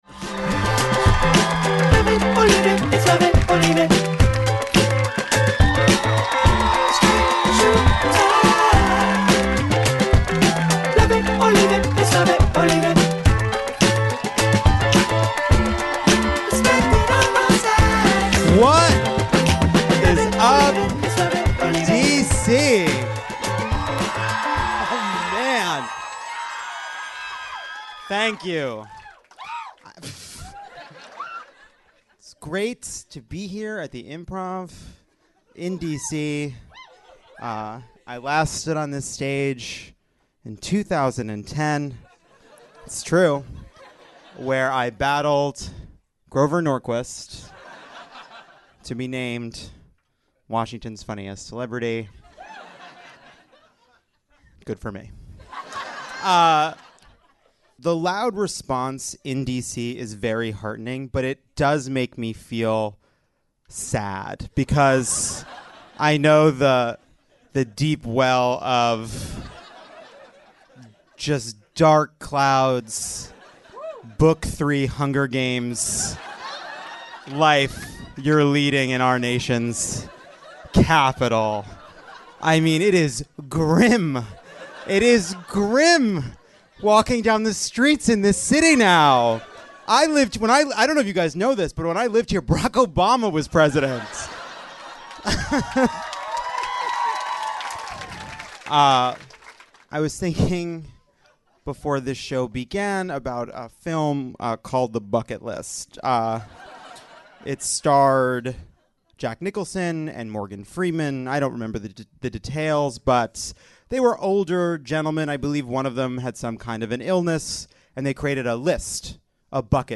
Trump hires John Bolton, congratulates Putin, attacks Mueller, loses his lawyer, and confronts very detailed accounts of marital affairs and efforts to cover them up. Plus, young people lead a march against gun violence, Mark Zuckerberg defends Facebook on TV, and Cynthia Nixon it turns out is not the character she played on Sex and the City. Recorded live in DC